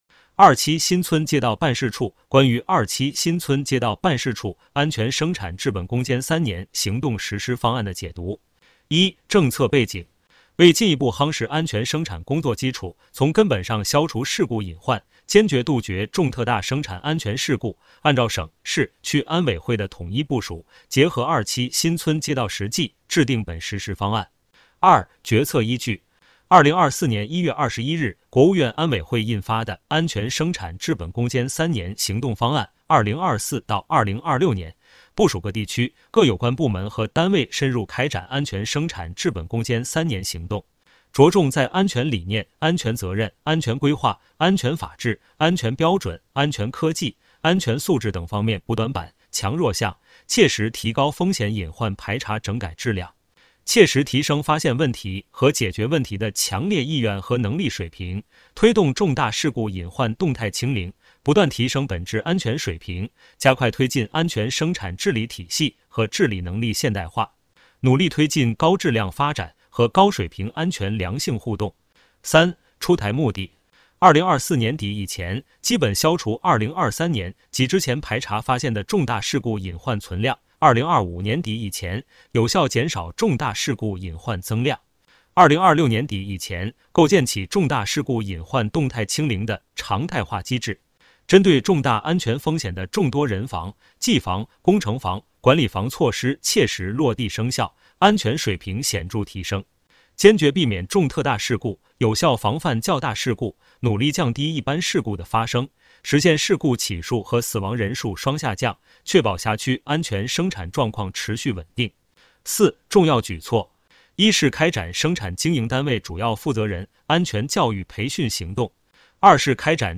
首页 > 政务公开 > 有声朗读 > 内容详情